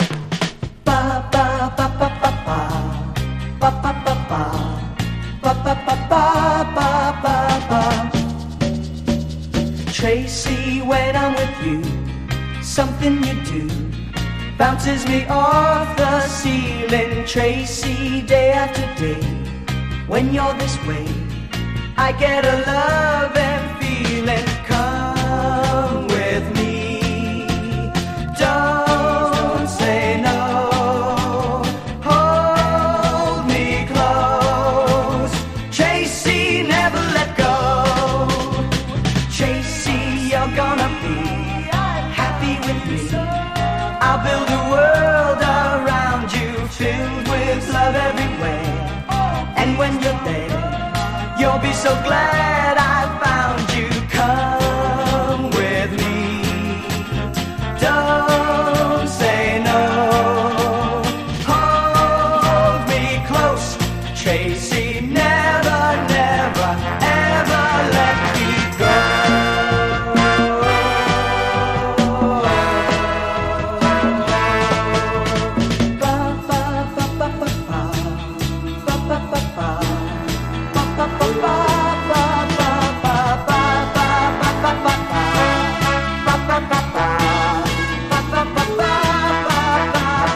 パパパ・コーラスが舞い上がるバブルガム・ソフトロック・クラシック！